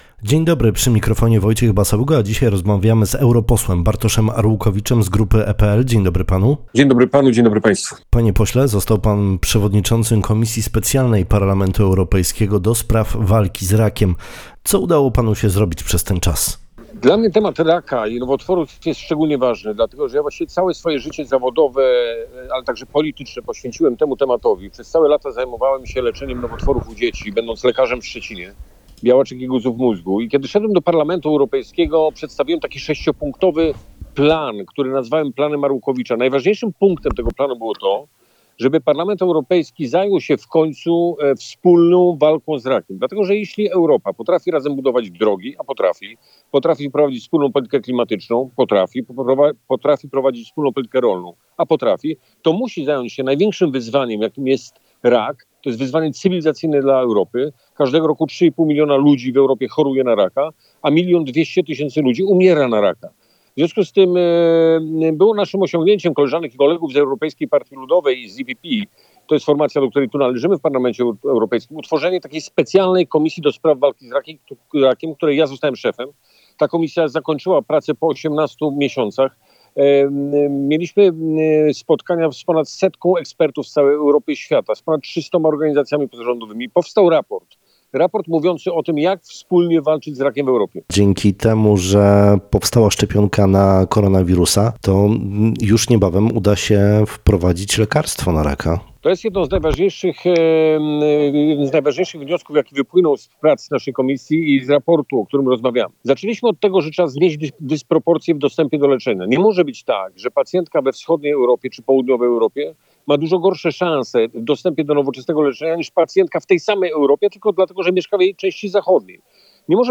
Zapraszamy do odsłuchania rozmowy z Bartoszem Arłukowiczem – eurodeputowany z ramienia Koalicji Europejskiej, który stoi na czele Komisji Specjalnej ds. Walki z Rakiem w Parlamencie Europejskim.
Rozmowa sponsorowana przez grupę EPL w Parlamencie Europejskim.